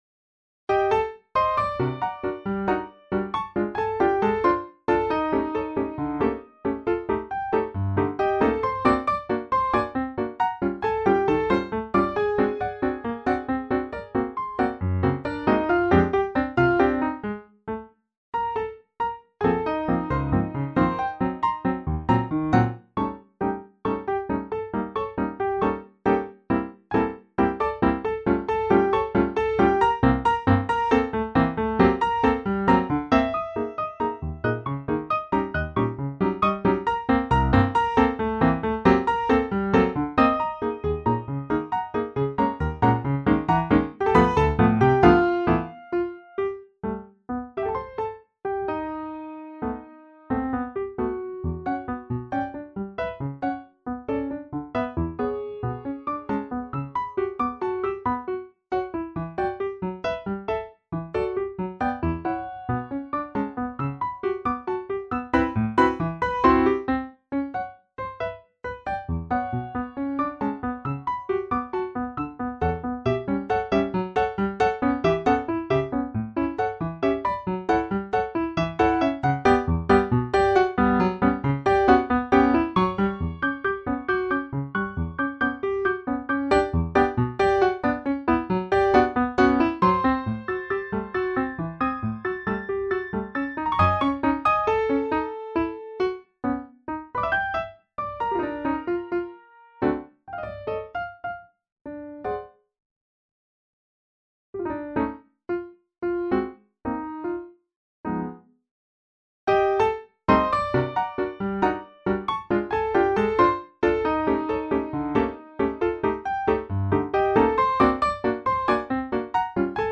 Piano duet